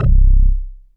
SYNTH BASS-1 0002.wav